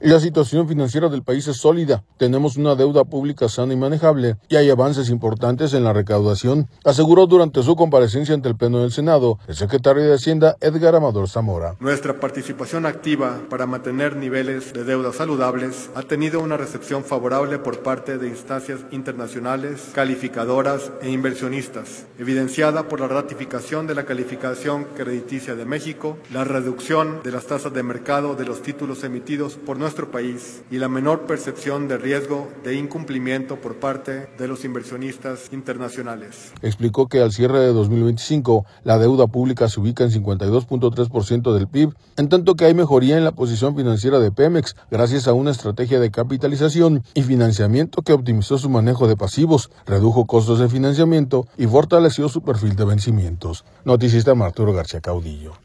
La situación financiera del país es sólida, tenemos una deuda pública sana y manejable, y hay avances importantes en la recaudación, aseguró durante su comparecencia ante el Pleno del Senado, el secretario de Hacienda, Edgar Amador Zamora.